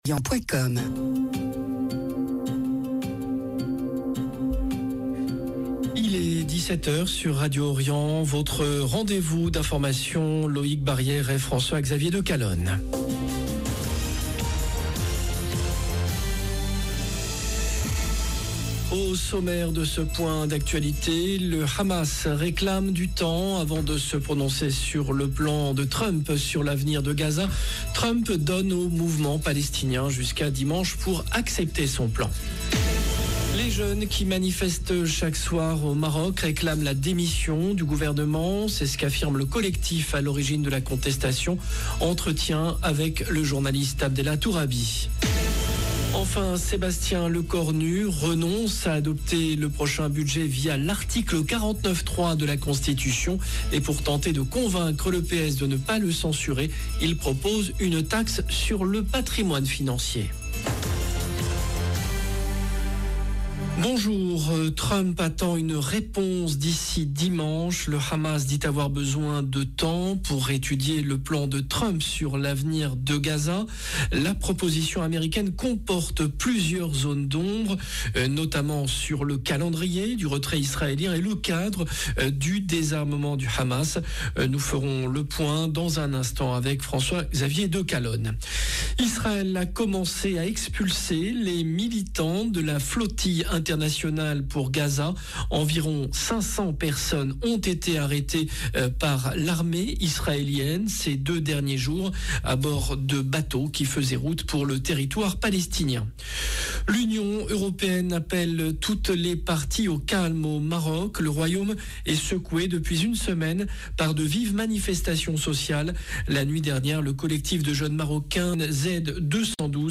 Entretien avec le journaliste